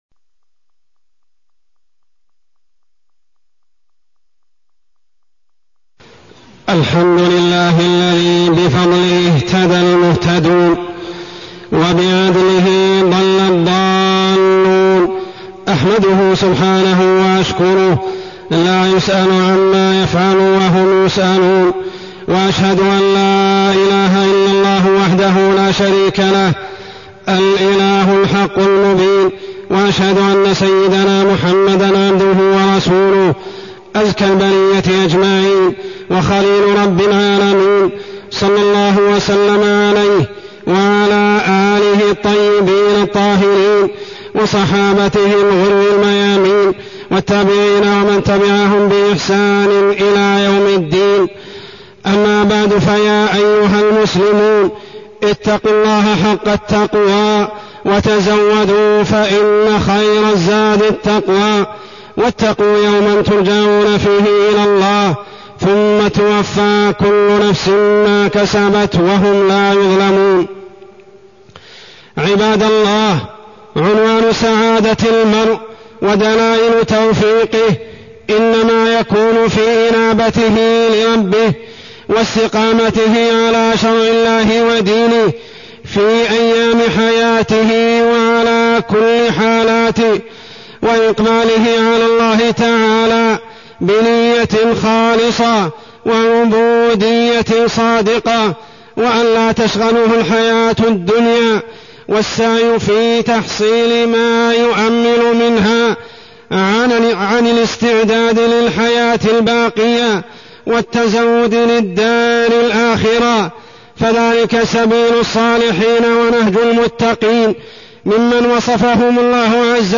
تاريخ النشر ١٣ جمادى الأولى ١٤٢٢ هـ المكان: المسجد الحرام الشيخ: عمر السبيل عمر السبيل هوان الحياة الدنيا